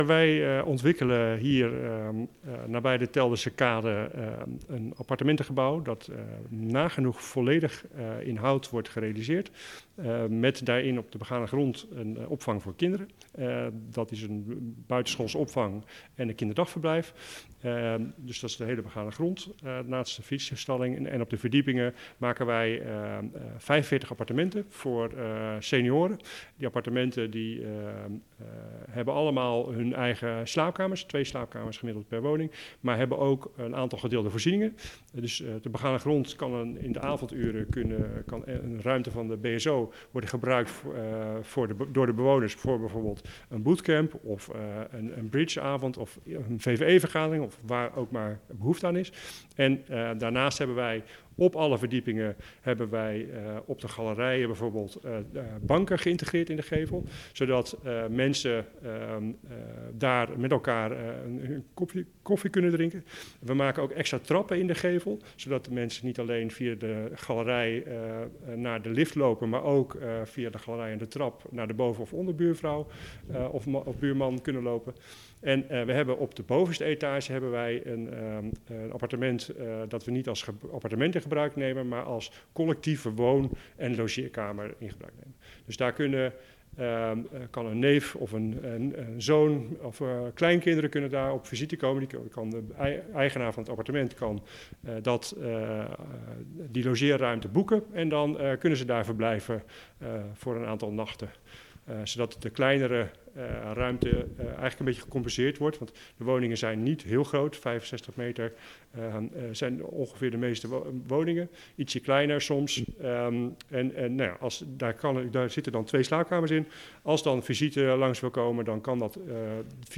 Verslaggever
in gesprek